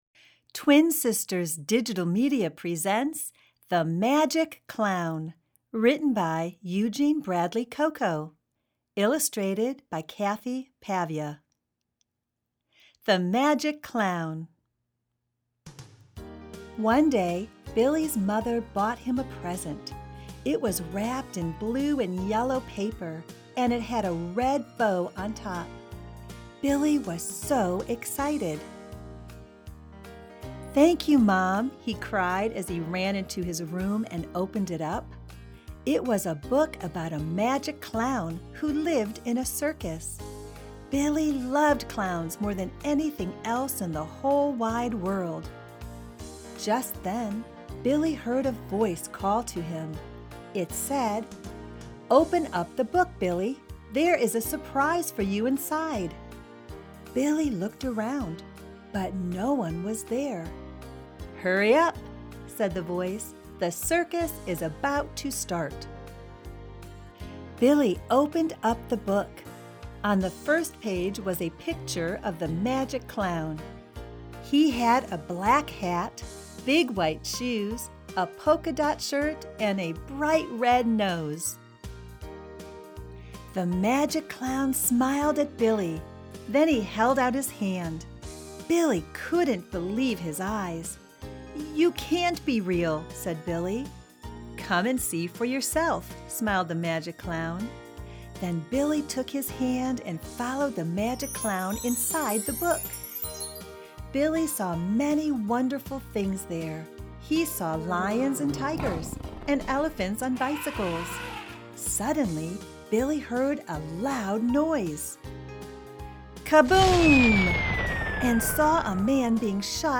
Reading The Magic Clown